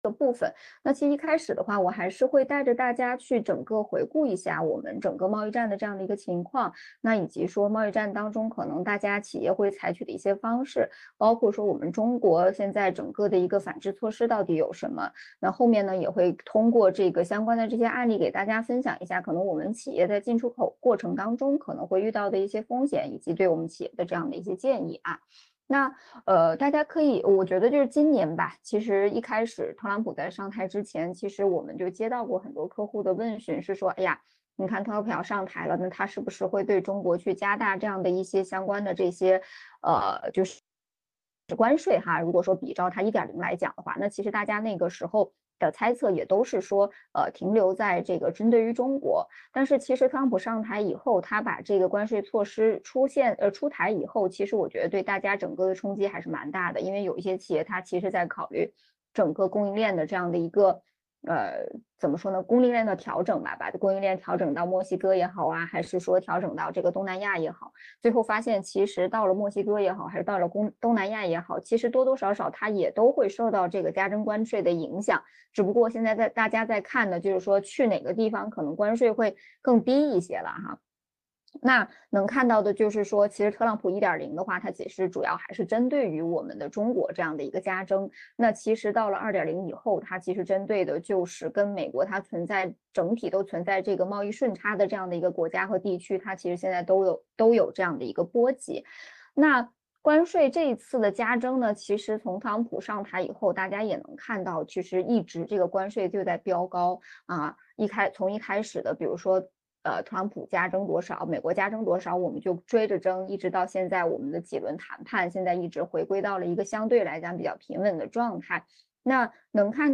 视频会议